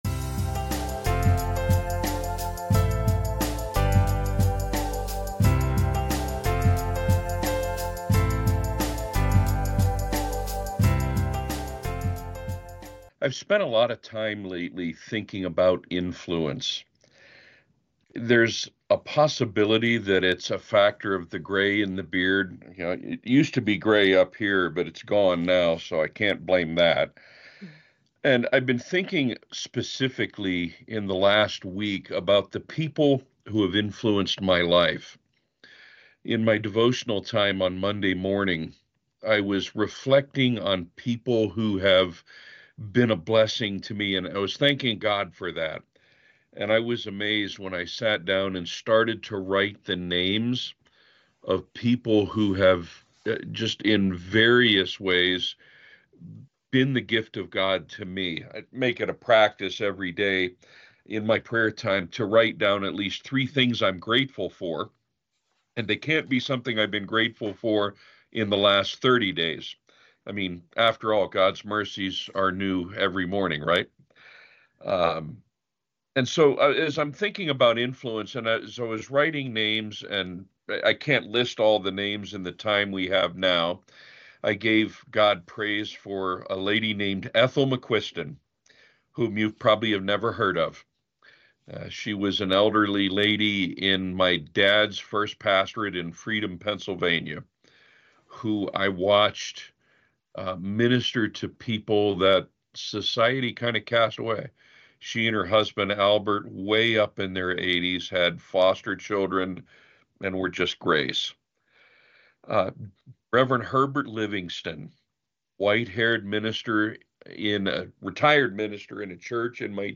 NBC Audio Chapel Services